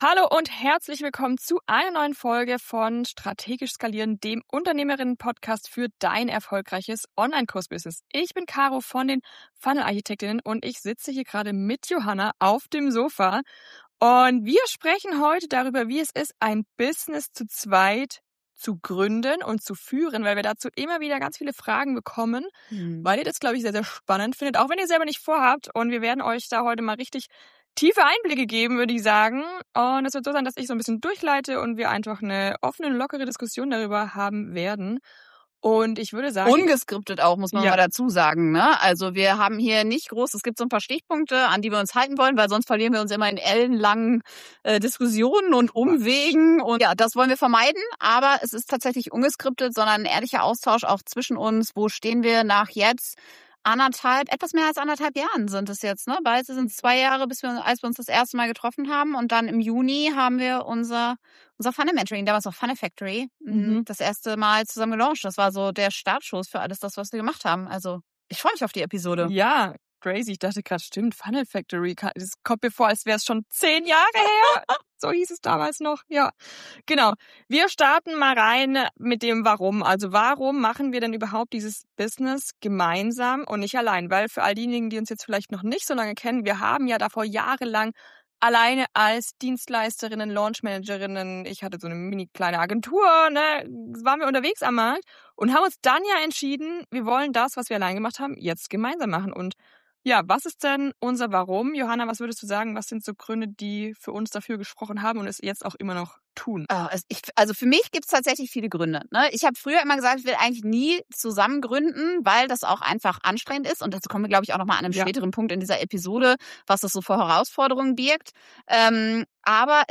In dieser sehr persönlichen, ungeskripteten Episode geben wir